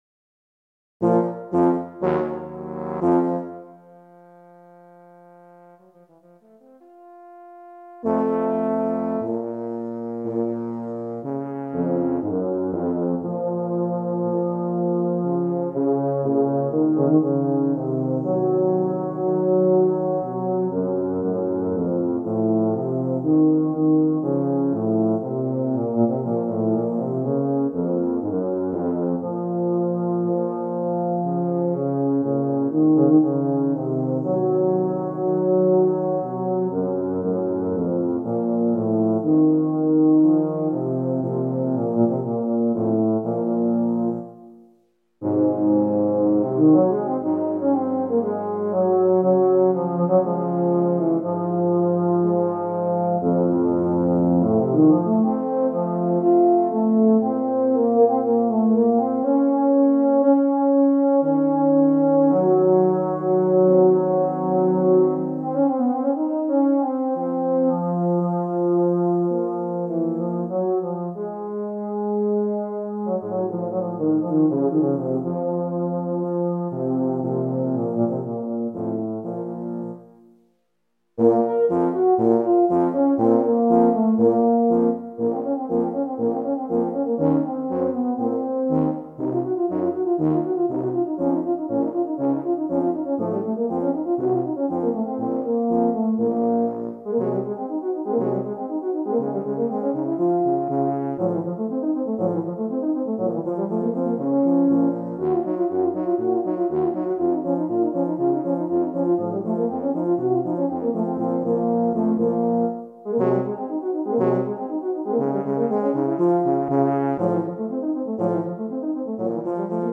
Voicing: Euphonium w/ Tuba Quartet